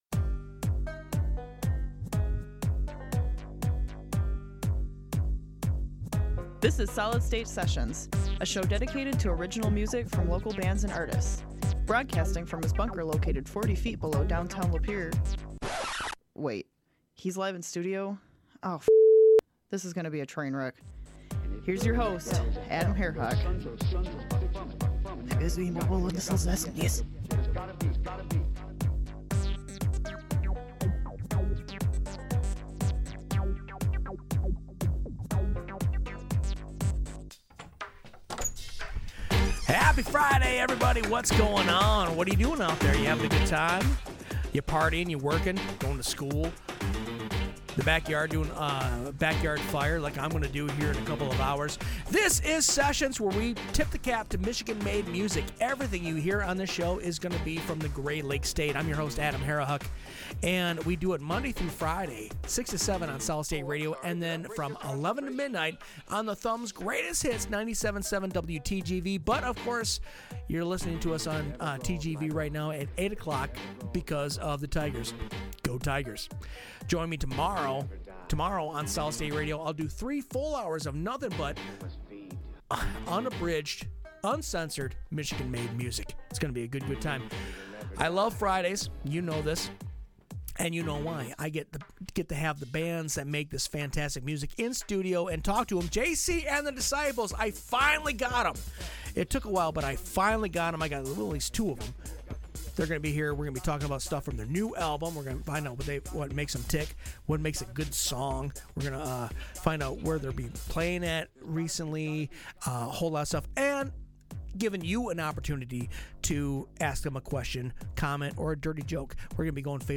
JC and the Disciples Interview